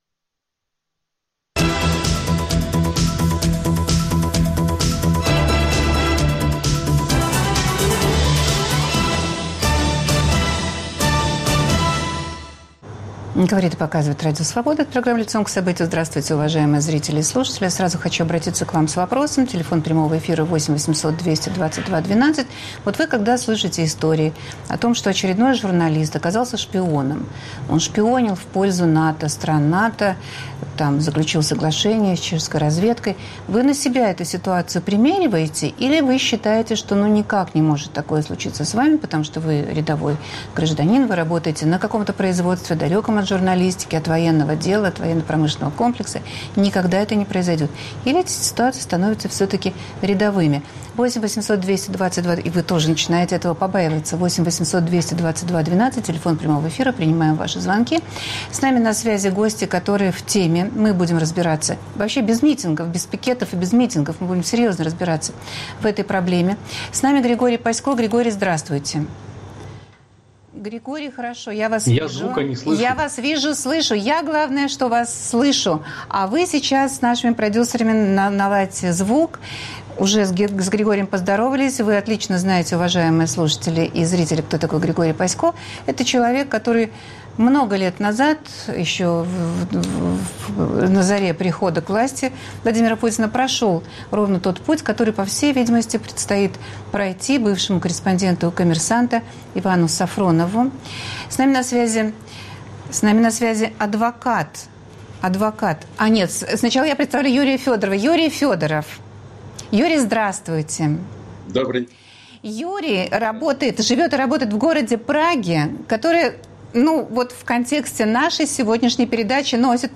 Должен ли каждый, кто специализируется в этой теме, быть готовым к аресту? Какие государственные секреты могут быть доступны людям, которые пишут об армии, ВПК и пр.? Обсуждают журналисты